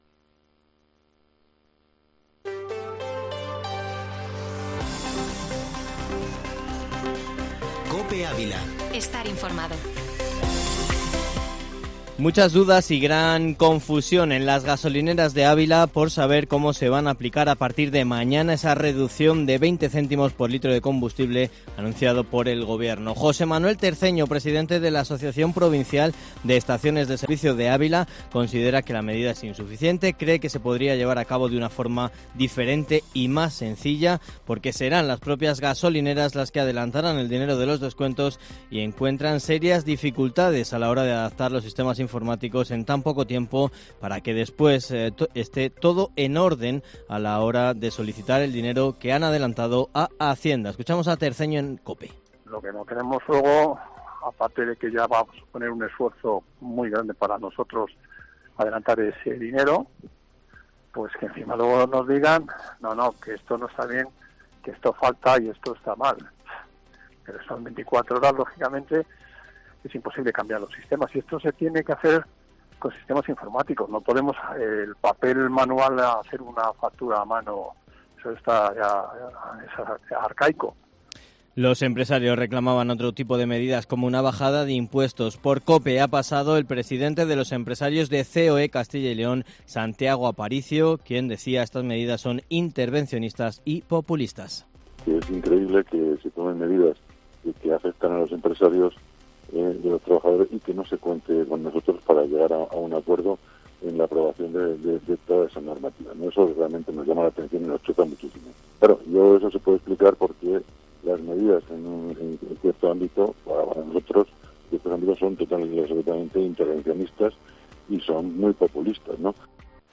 Informativo Matinal Herrera en COPE Ávila -31-marzo